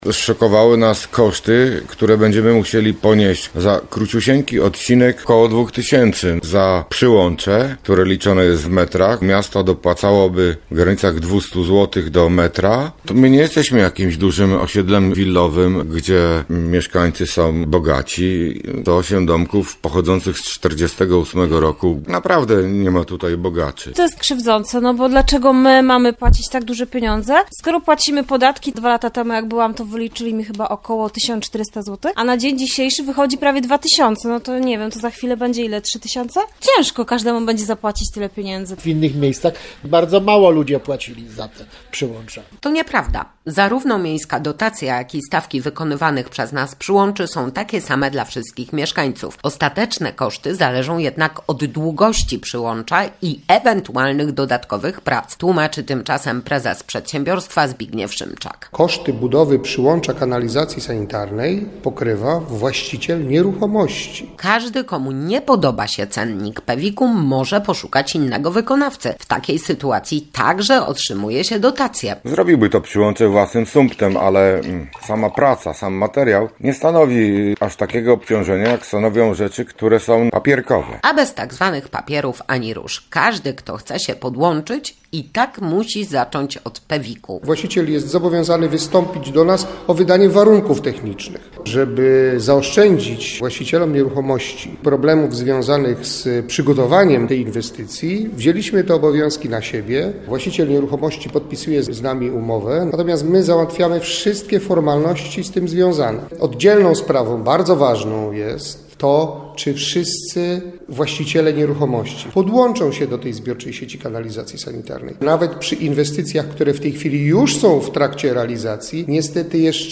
Dlaczego mieszkańcy Konina najpierw zabiegają o budowę kanalizacji, a później nie chcą się do niej podłączać? Posłuchajmy ich argumentów